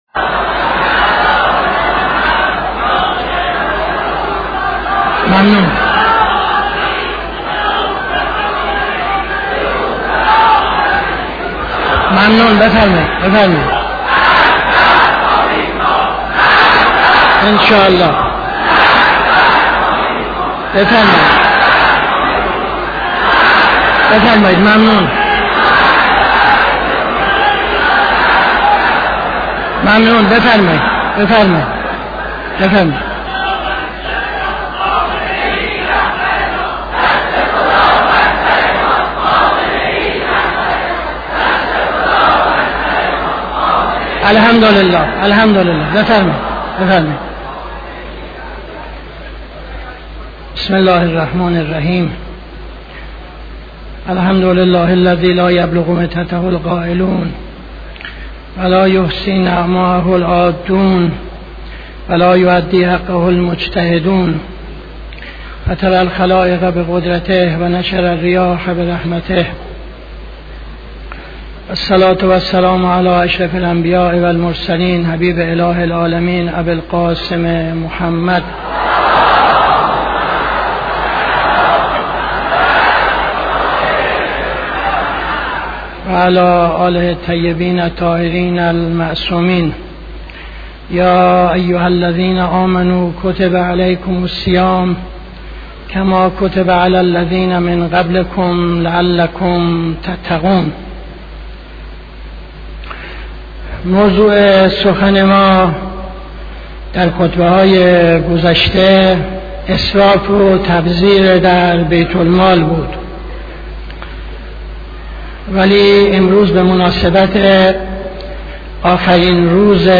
خطبه اول نماز جمعه 21-10-75